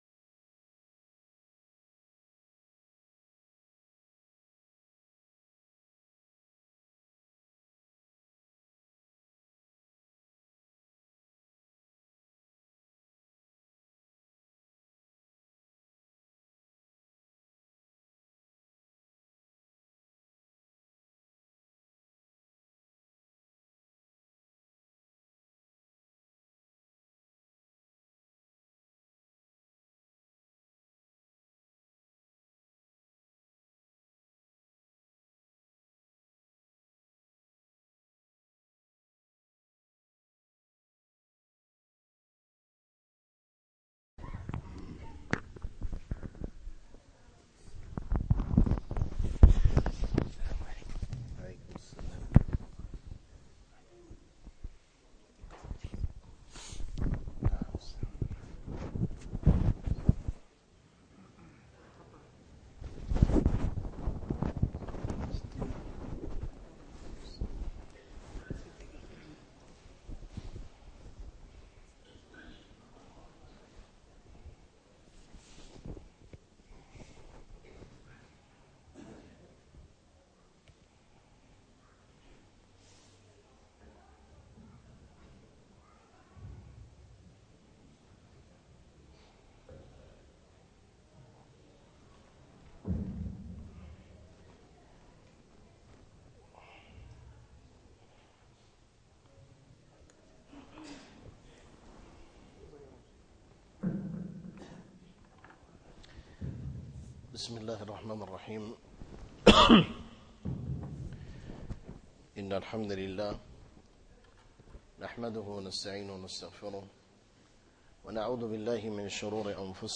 الدرس 8 - الاربعين النووية (الحديث الثاني د 2)